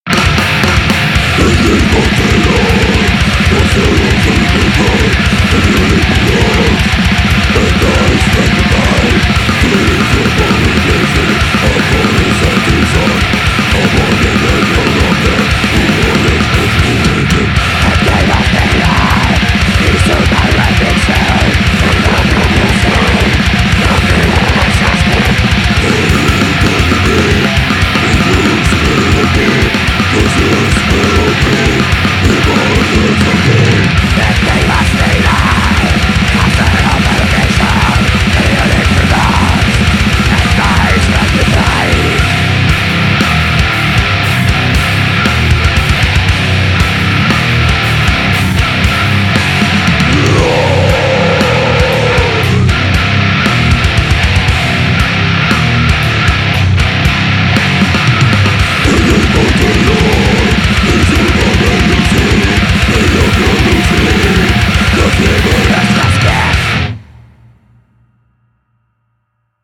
Ének